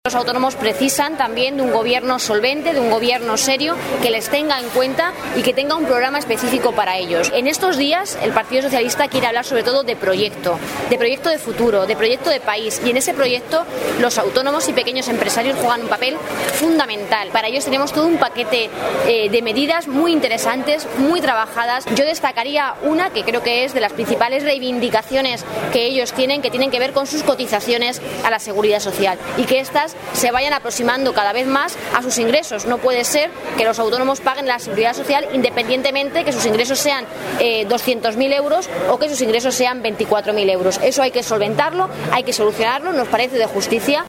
Durante su visita al mercado de abasto de Ciudad Real
Cortes de audio de la rueda de prensa